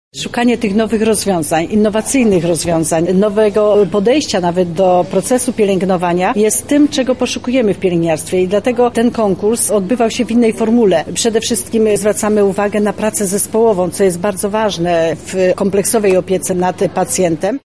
Podczas spotkania odbyła się także konferencja z udziałem wiceminister zdrowia.
-mówi Józefa Szczurek-Żelazko, wiceminister zdrowia.